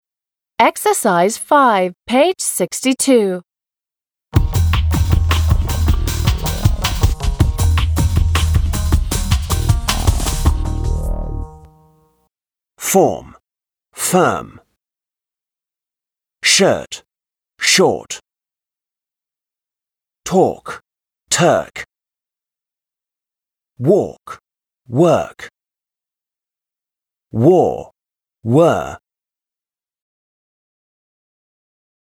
/ɔ: / − читается как «о»